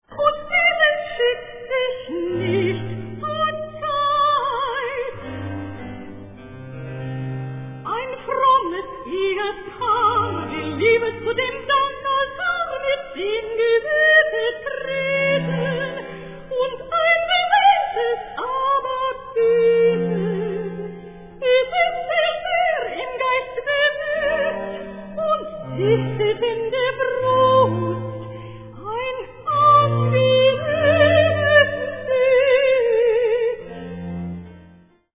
Three virtuosic soprano solo cantatas
Performed on period instruments.
13. Recitative: